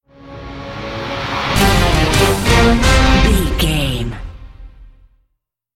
Ionian/Major
C#
horns
drums
electric guitar
synthesiser
orchestral
orchestral hybrid
dubstep
aggressive
energetic
intense
strings
bass
synth effects
wobbles
heroic
driving drum beat
epic